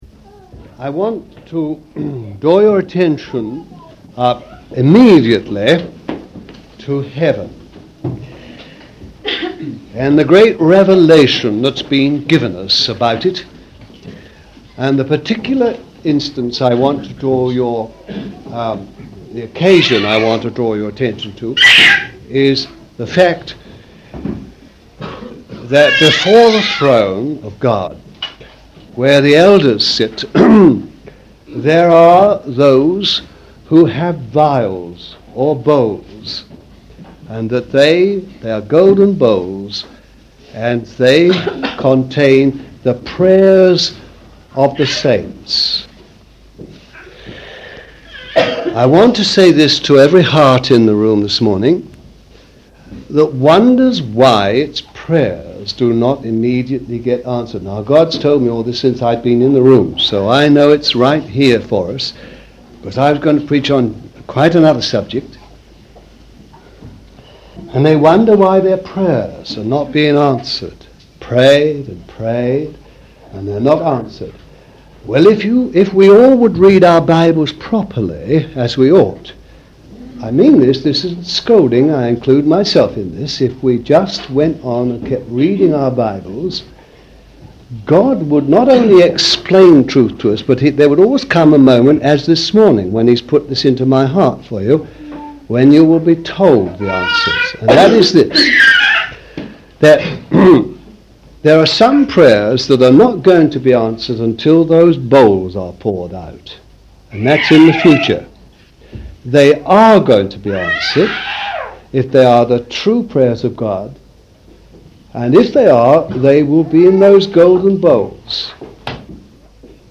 Given at the morning meeting in Auchenheath CF in 1982